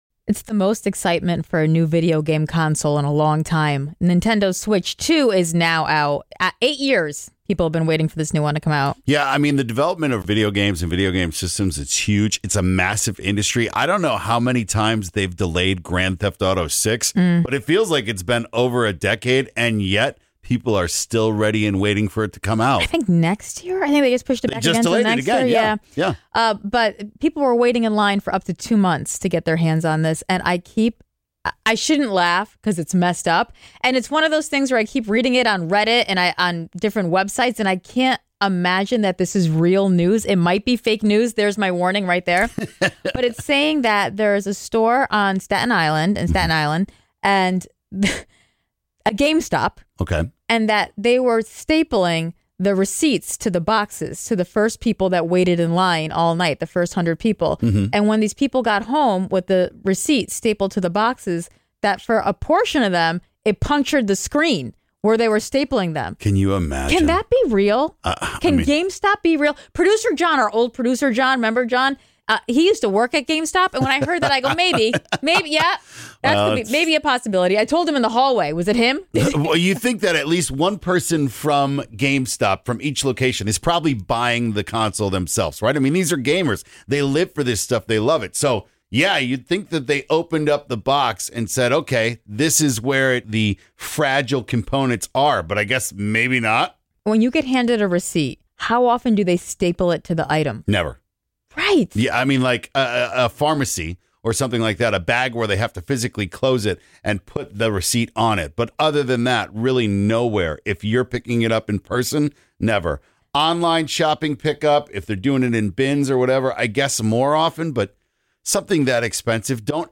Listeners call and share something that went terribly wrong at work